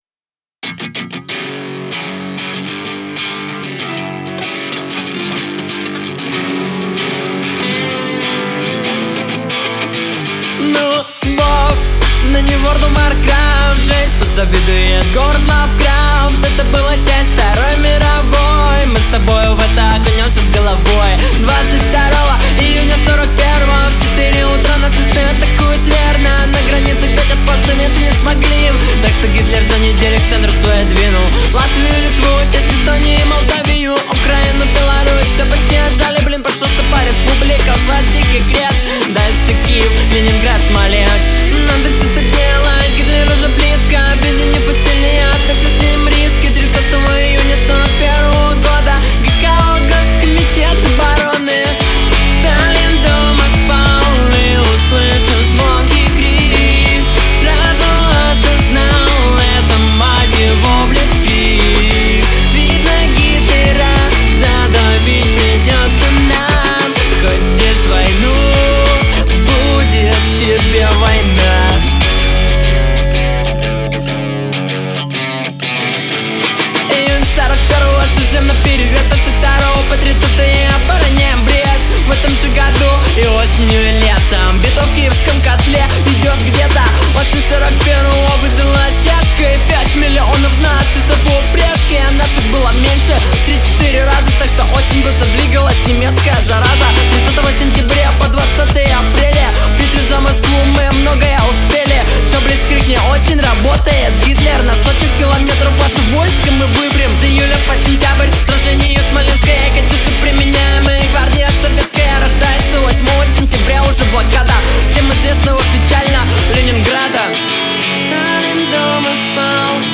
Русский Рок